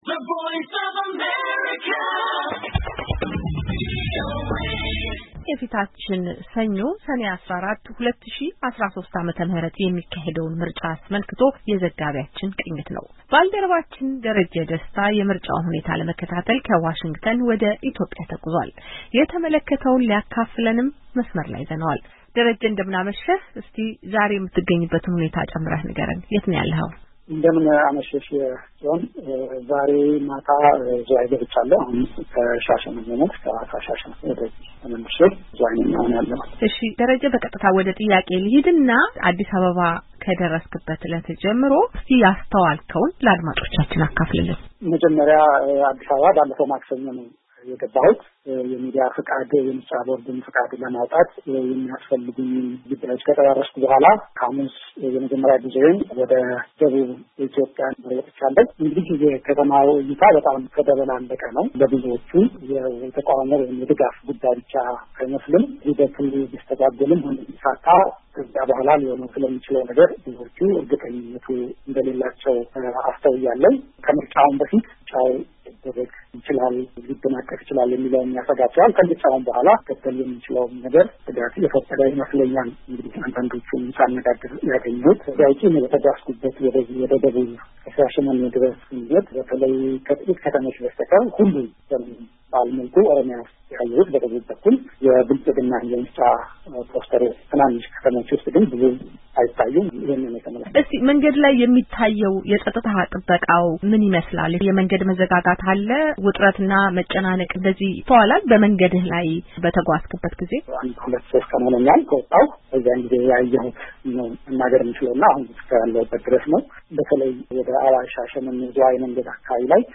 ምልልስ